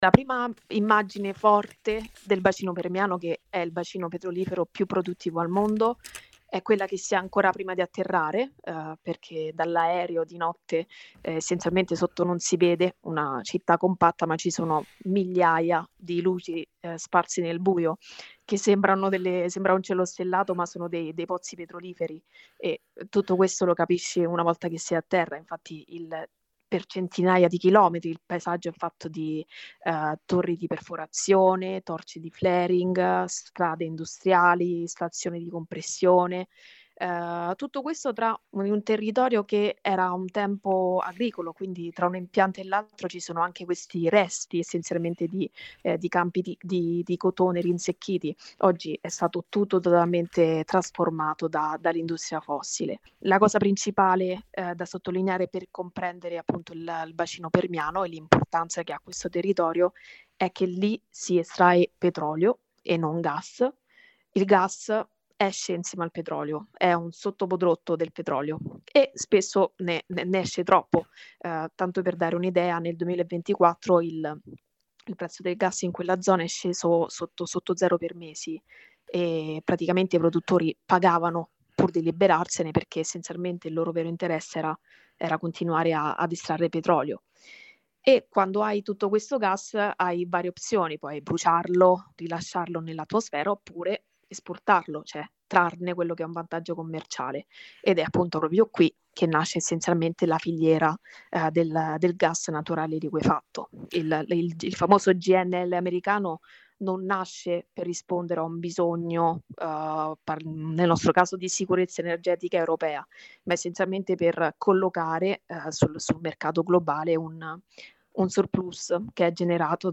Viaggio nel bacino Permiano, il più grande giacimento di petrolio degli Stati Uniti, dove viene prodotto il gas naturale per l'Europa e l'Italia. Dal golfo del Messico parte il gas a bordo delle navi dirette verso l'Europa. L'intervista